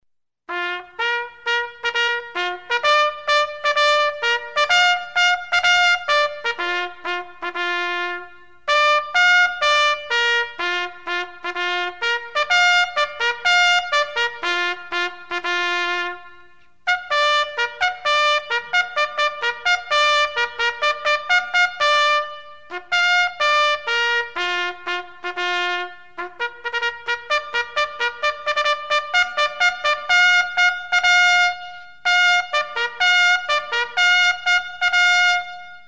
Звуки горна
8. Военный